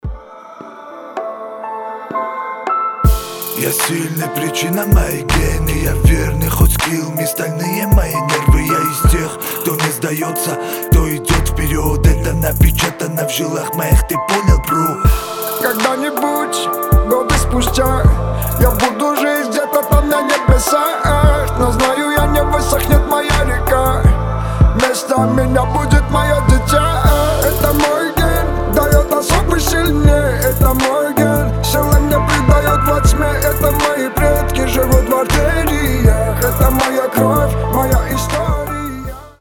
• Качество: 320, Stereo
русский рэп
мотивирующие
спокойные
лирические